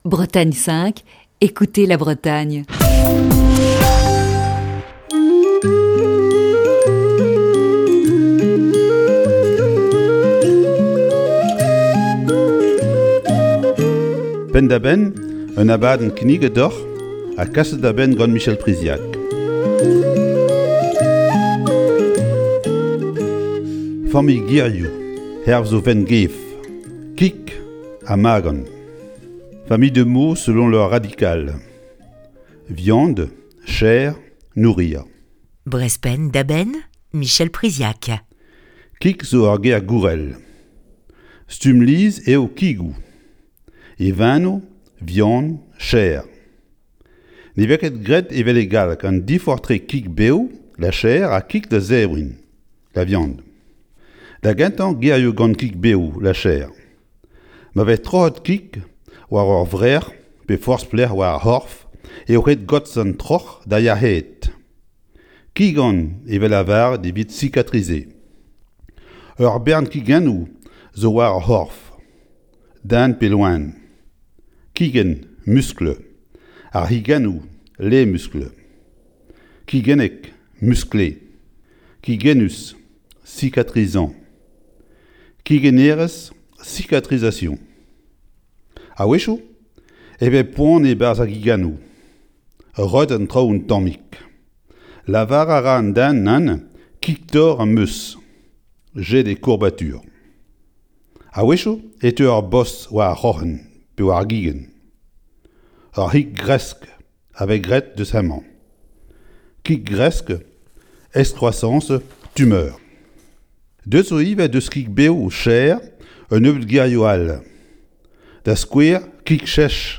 Cours de breton ce jeudi dans Breizh Penn da Benn.